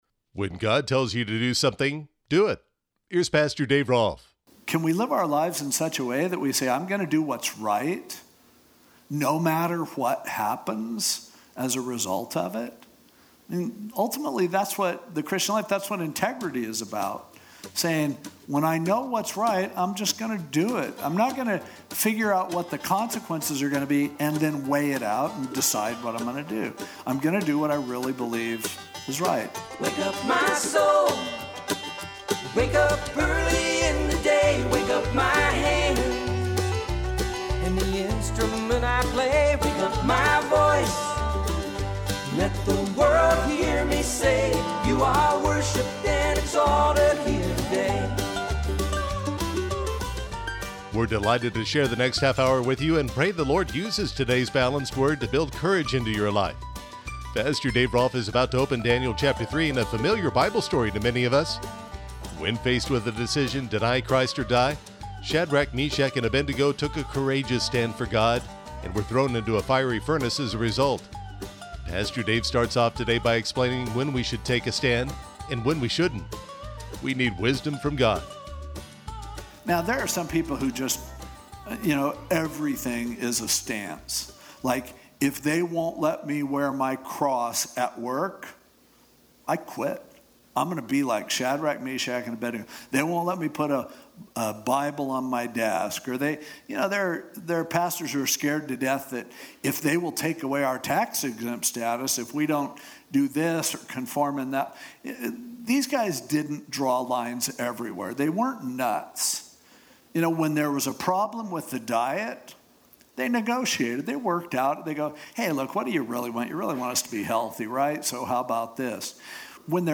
podcasted radio messages